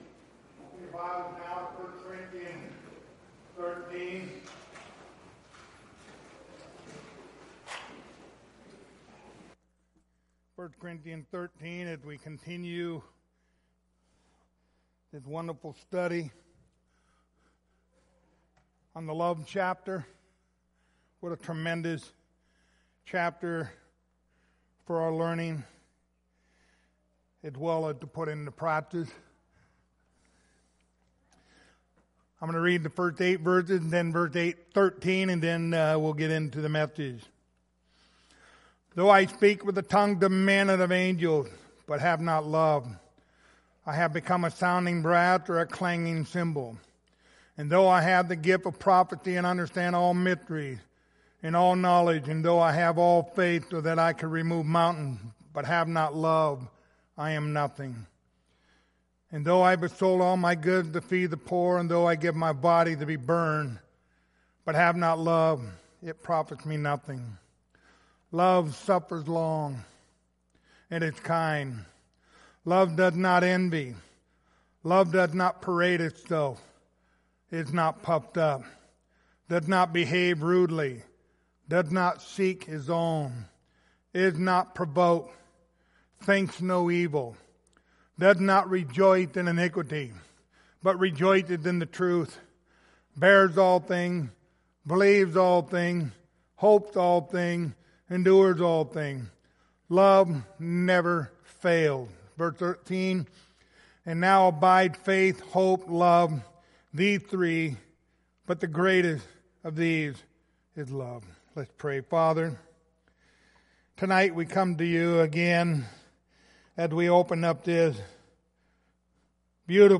Passage: 1 Corithians 13:5 Service Type: Wednesday Evening